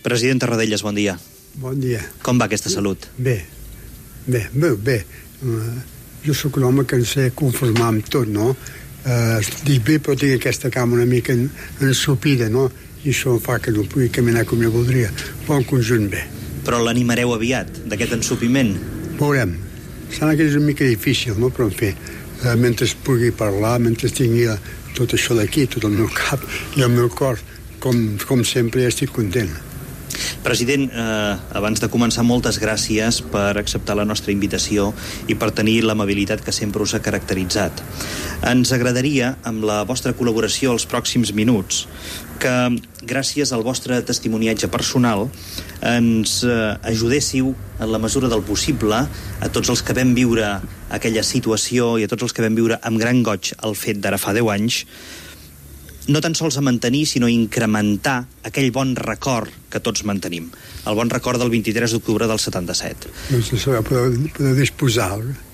Fragment d'una entrevista al president de la Generalitat Josep Tarradellas, deu anys després del seu retorn a Catalunya.
Info-entreteniment